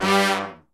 G2 POP FAL.wav